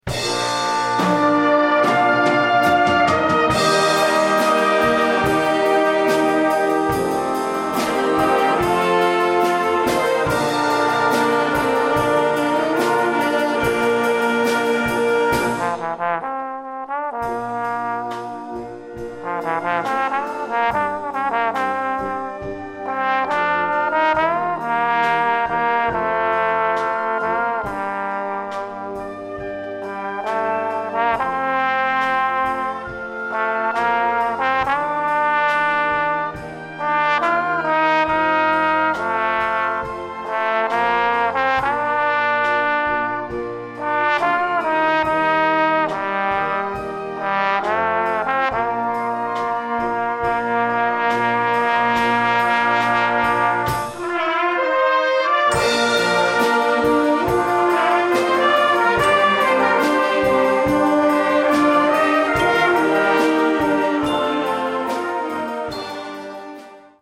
Gattung: Solo für Posaune und Blasorchester
Besetzung: Blasorchester